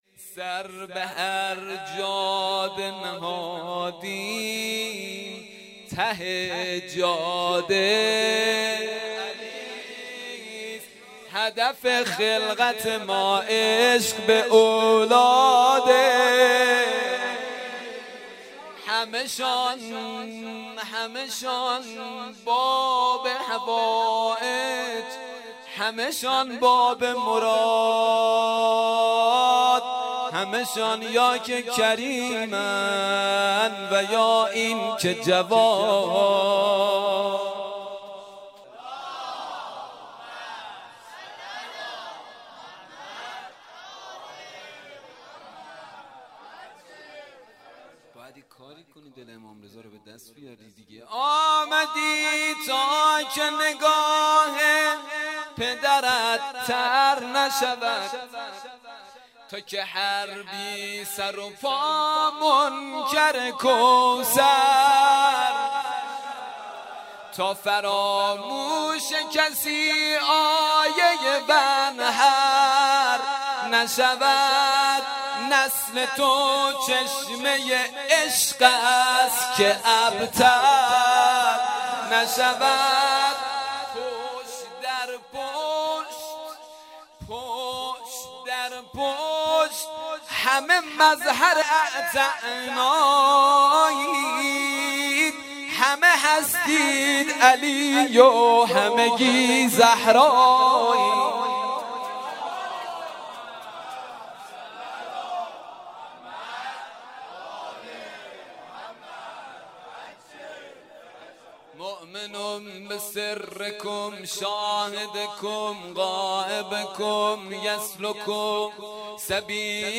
حسینیه بیت النبی